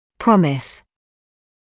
[‘prɔmɪs][‘промис]обещать; обещание